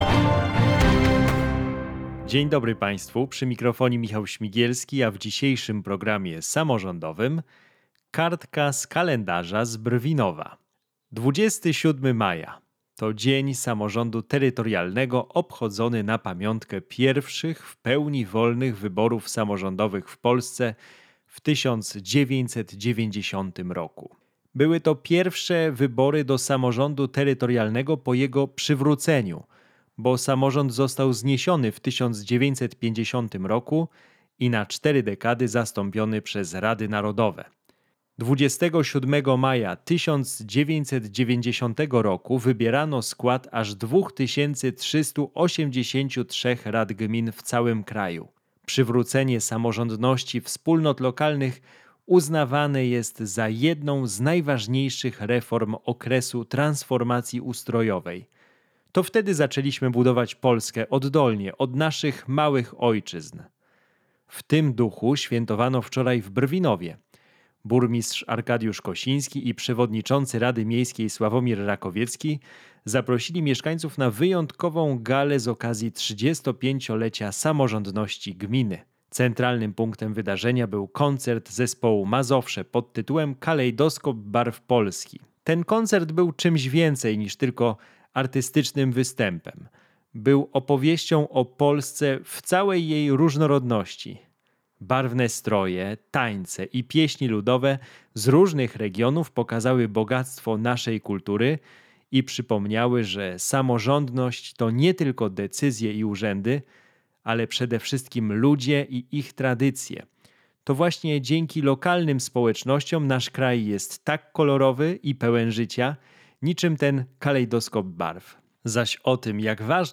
Radio Niepokalanów - relacja z gali 35-lecia Samorządności Gminy Brwinów
Relacja z gali 35-lecia Samorządności Gminy Brwinów, która odbyła się 27 maja 2025 r.